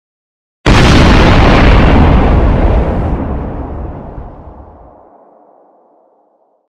دانلود آهنگ انفجار 1 از افکت صوتی طبیعت و محیط
دانلود صدای انفجار 1 از ساعد نیوز با لینک مستقیم و کیفیت بالا
جلوه های صوتی